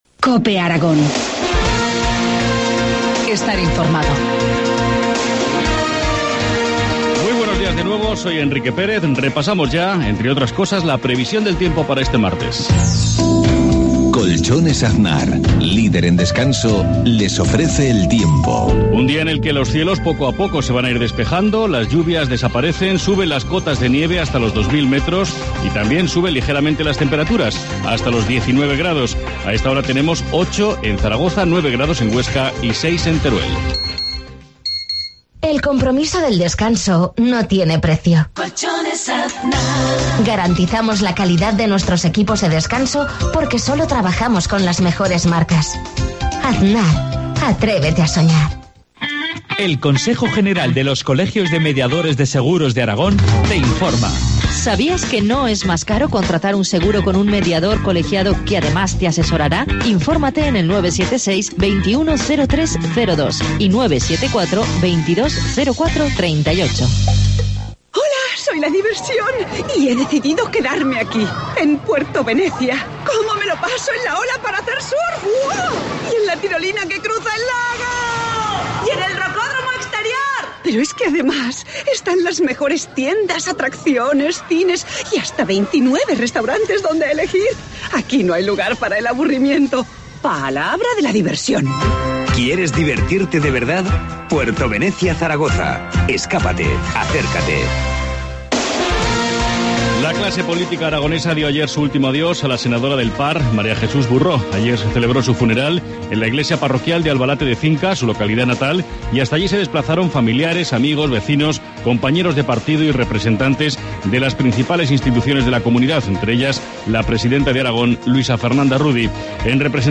Informativo matinal, martes 21 de mayo, 7.53 horas